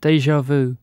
A déjà vu (ejtsd: dézsá vü)Hallgat
En-uk-dejavu.ogg